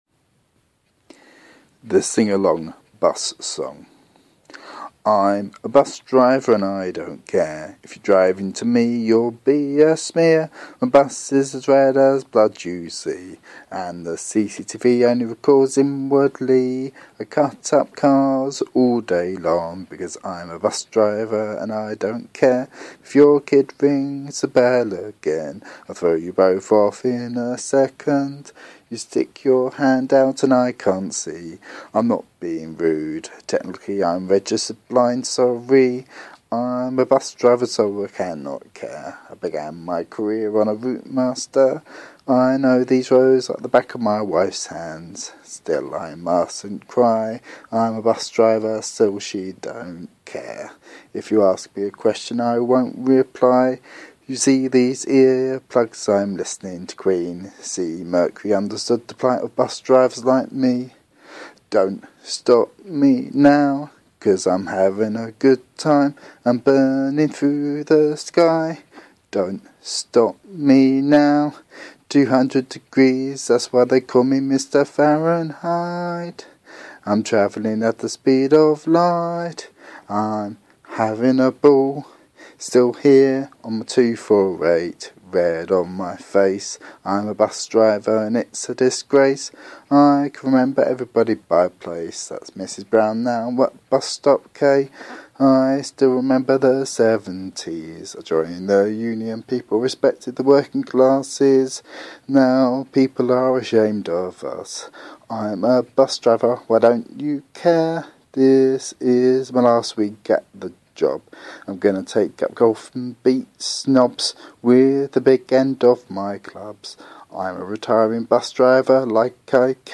Comedy sung poem